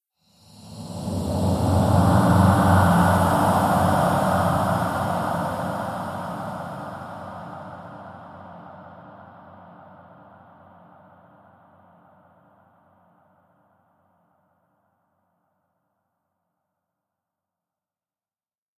Смеющийся и пролетающий призрак
smeyushijsya_i_proletayushij_prizrak_f3h.mp3